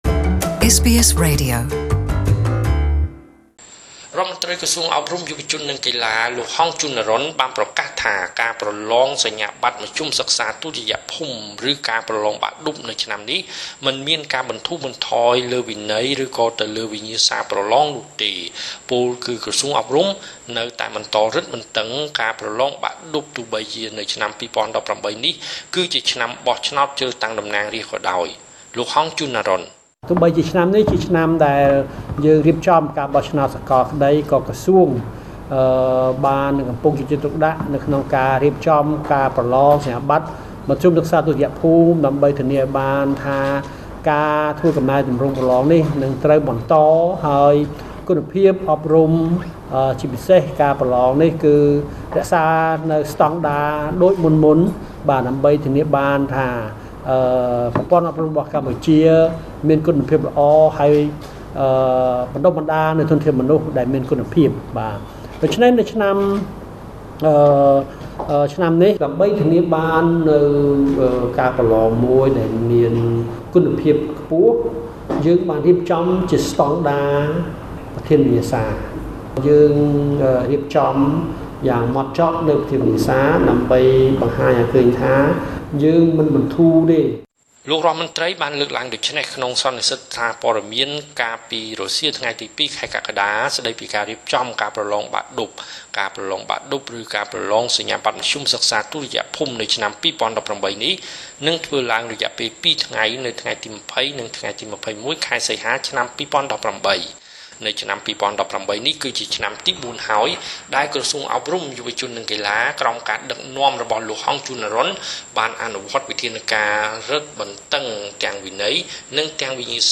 សេចក្តីរាយការណ៍ពិស្តារ។